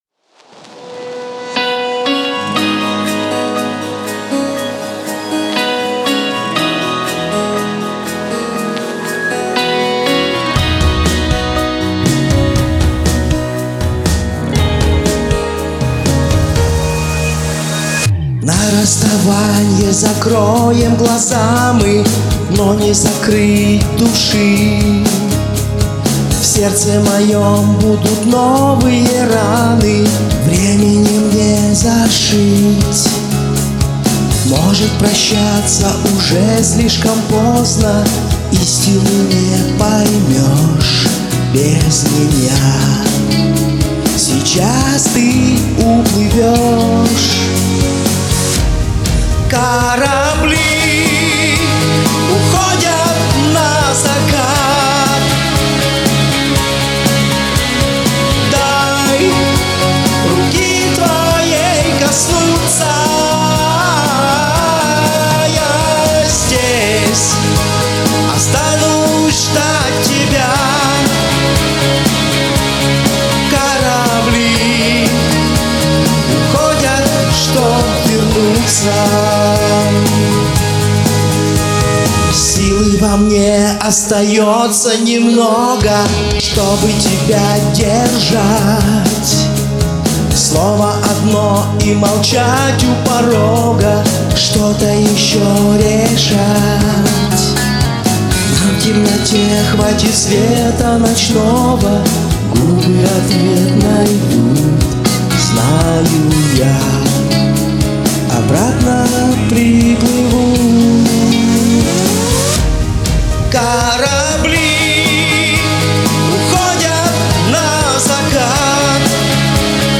У обоих исполнителей прекрасный вокал.